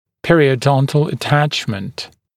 [ˌperɪəu’dɔntl ə’tæʧmənt][ˌпэриоу’донтл э’тэчмэнт]пародонтальное прикрепление